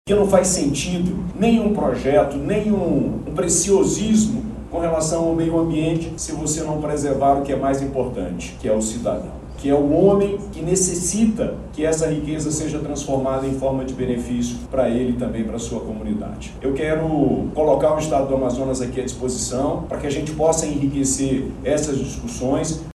Durante o evento, o governador Wilson Lima falou que o meio ambiente é importante, mas que é preciso também pensar nas pessoas que vivem na região. Para ele, os recursos naturais precisam melhorar a vida da população, gerando emprego e infraestrutura.
SONORA-1-GOVERNADOR.mp3